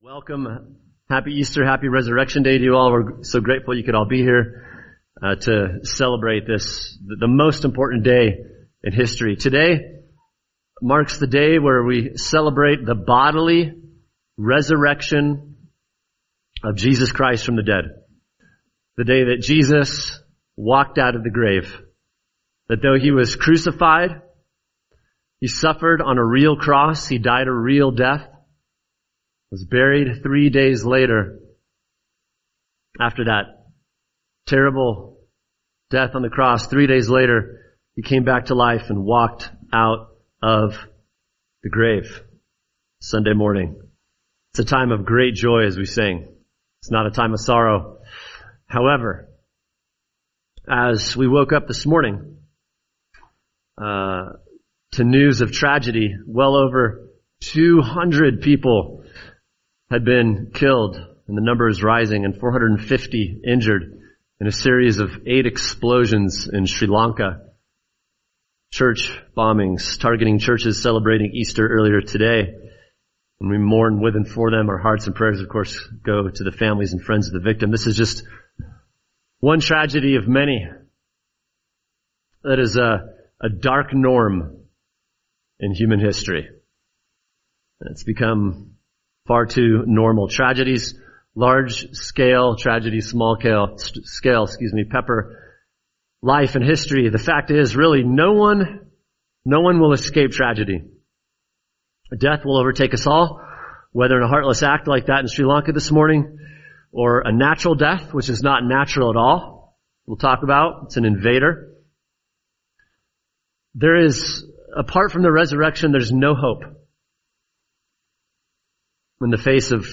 [sermon] 1 Corinthians 15:20-28 Resurrection Restoration | Cornerstone Church - Jackson Hole